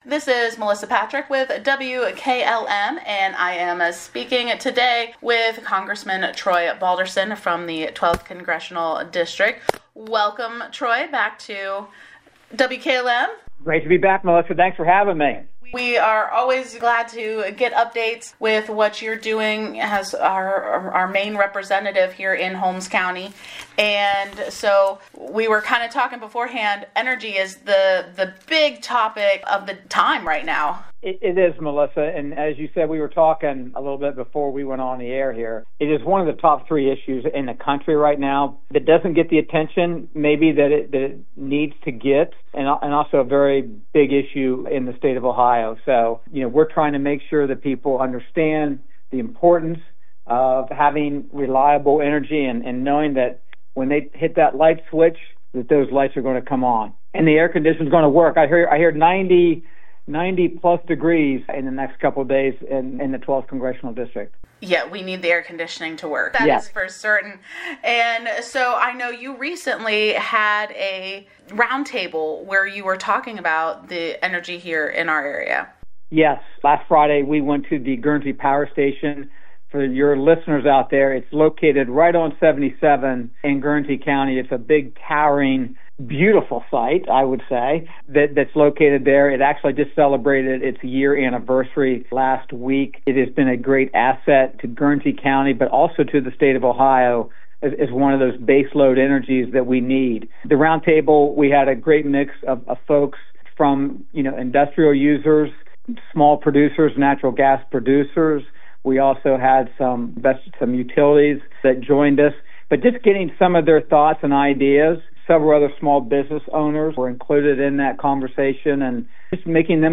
6-14-24 Interview with Congressman Troy Balderson